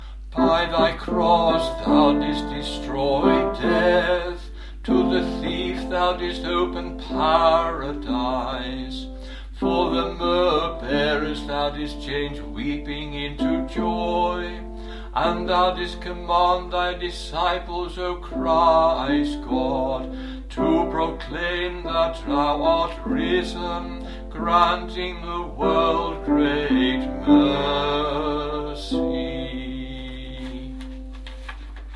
TONE 7 TROPARION
tone-7-troparion.mp3